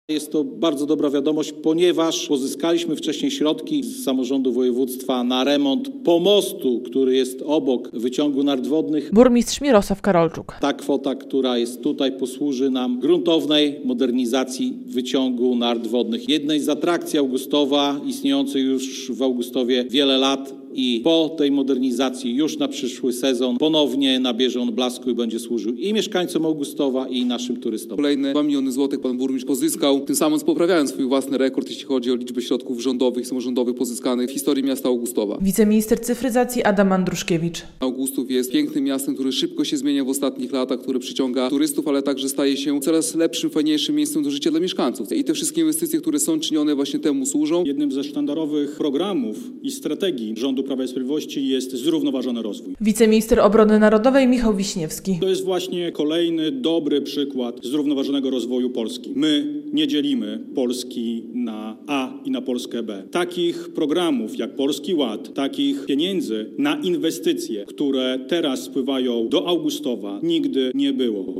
2 miliony złotych dla Augustowa na remont wyciągu nart wodnych - relacja